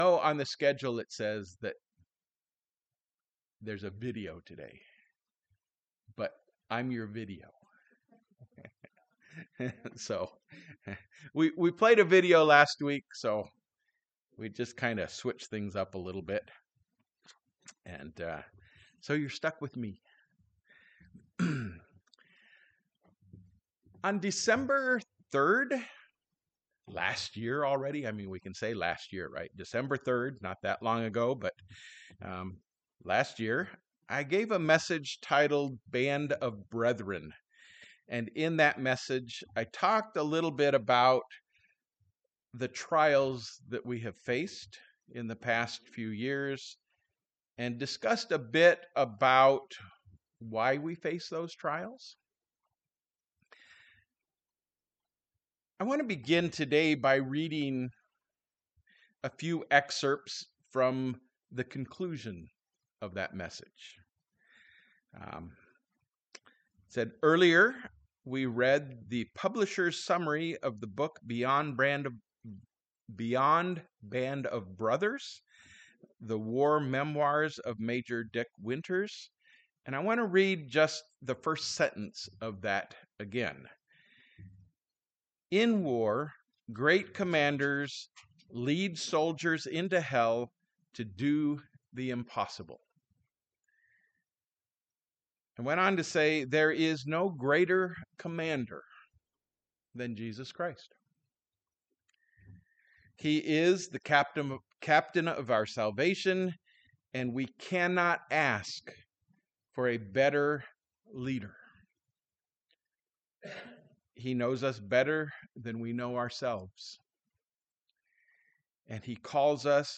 On December 3, I gave a message titled, Band of Brethren.